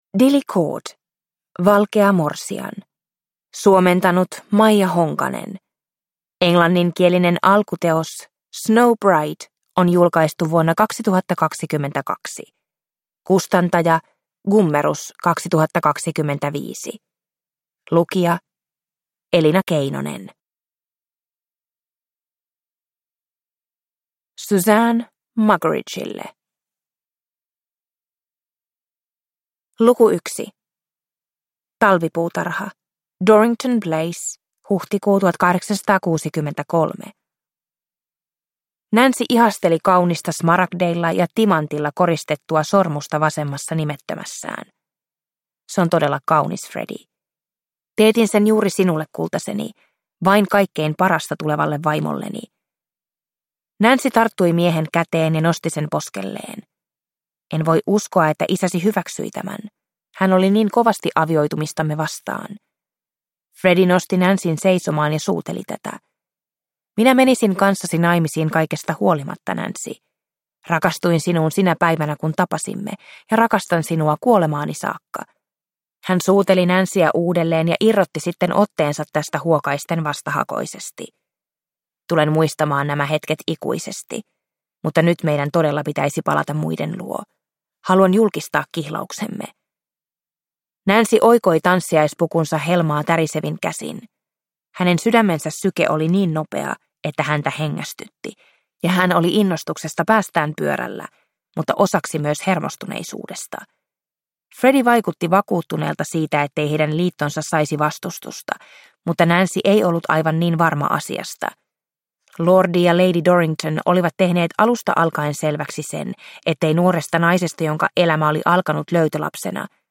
Valkea morsian (ljudbok) av Dilly Court | Bokon